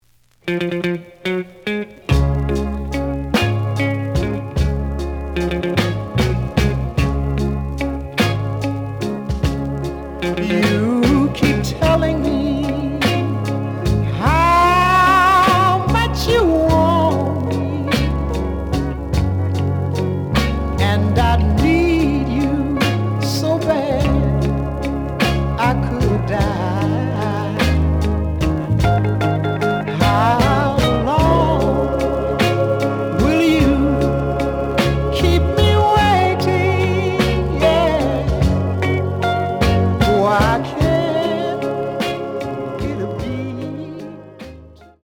The audio sample is recorded from the actual item.
●Genre: Soul, 60's Soul
Looks good, but slight noise on A side.)